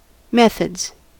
methods: Wikimedia Commons US English Pronunciations
En-us-methods.WAV